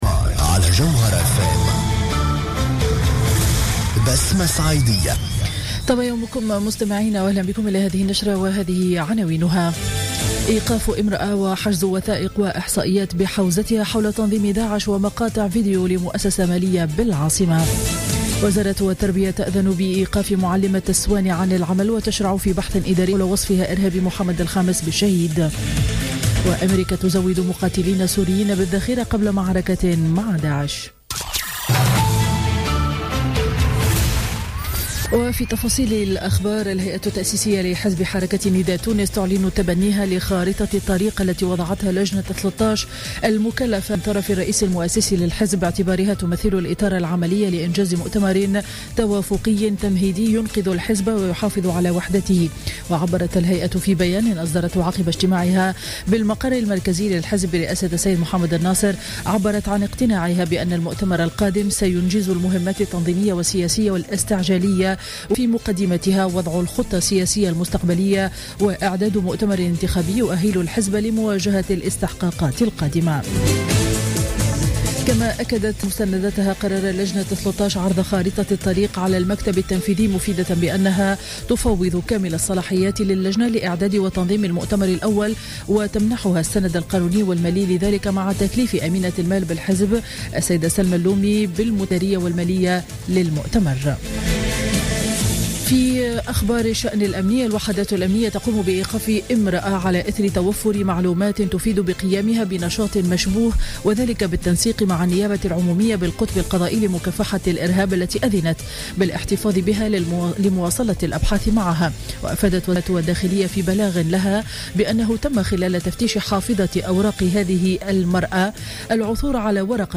نشرة أخبار السابعة صباحا ليوم الخميس 17 ديسمبر 2015